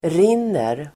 Uttal: [r'in:er]